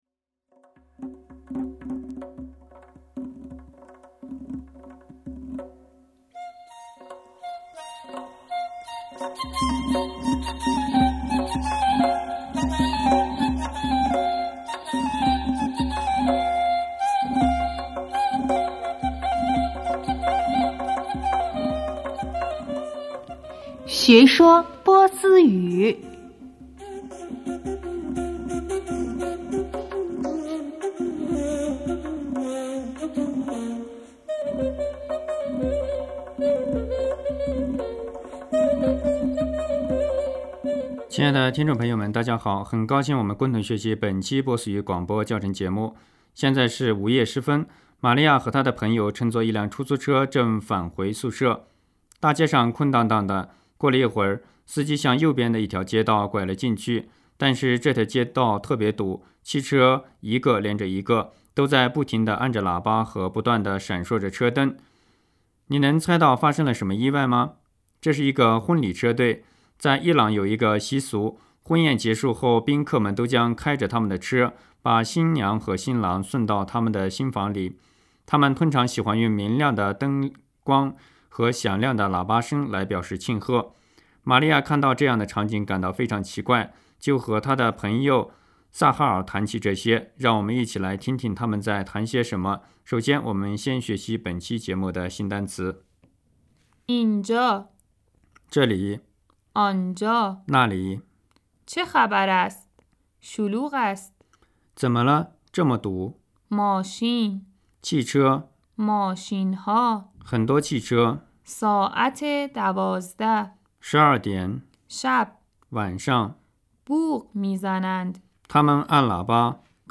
（在街上，汽车和喇叭的声音） 玛利亚：这里发生什么事了？